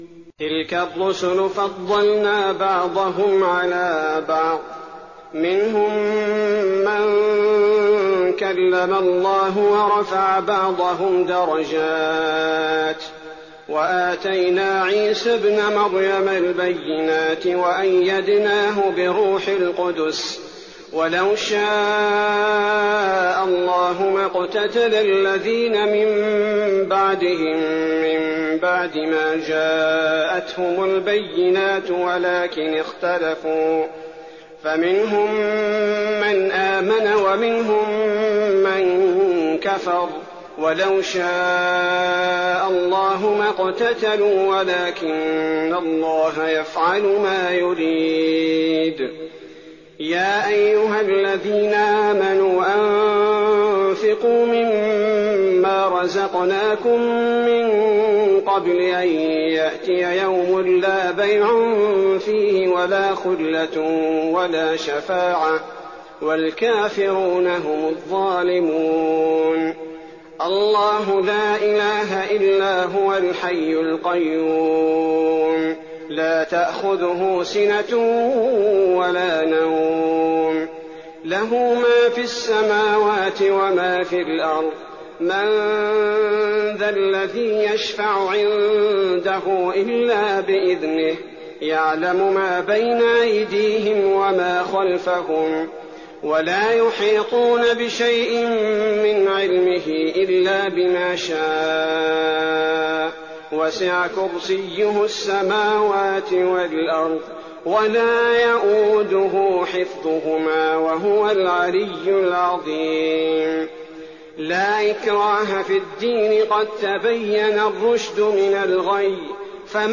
تراويح الليلة الثالثة رمضان 1419هـ من سورتي البقرة (253-286) وآل عمران (1-17) Taraweeh 3rd night Ramadan 1419H from Surah Al-Baqara and Surah Aal-i-Imraan > تراويح الحرم النبوي عام 1419 🕌 > التراويح - تلاوات الحرمين